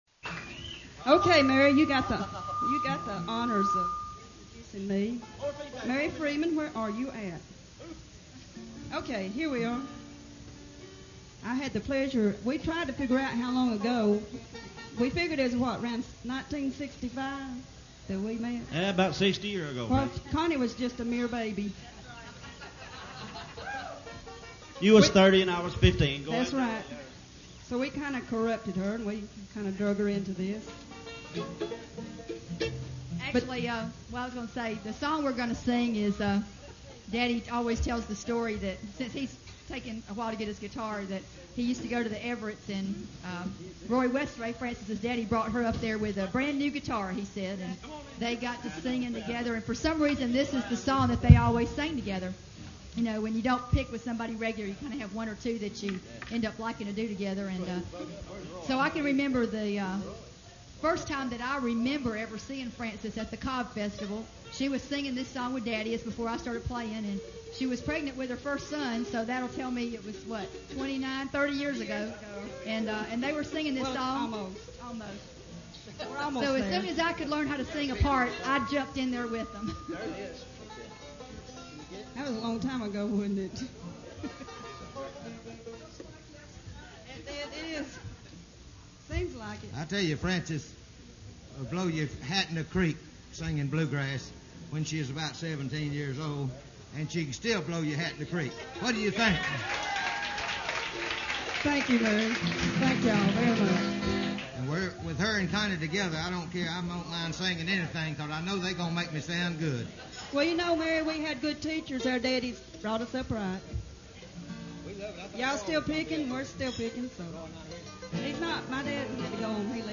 guitar
bass
mandolin
banjo.
Introduction of Special Guest